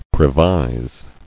[pre·vise]